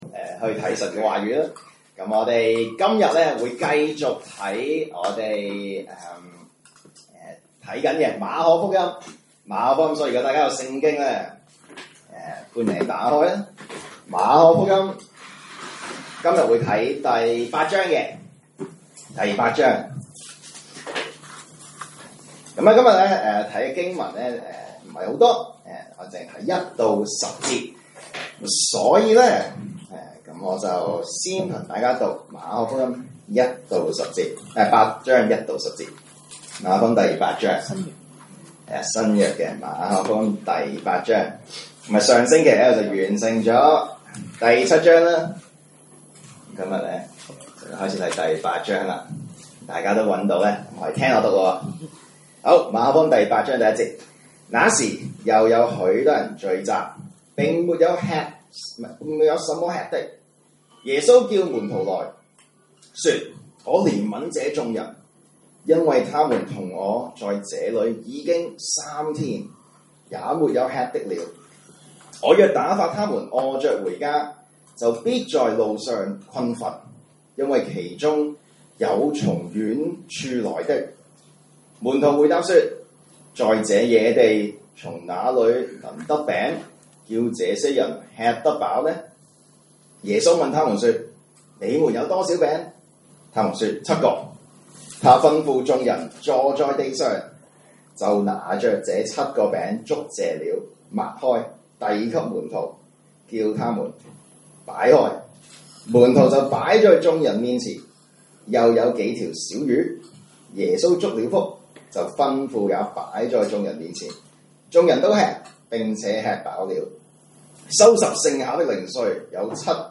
來自講道系列 "查經班：馬可福音"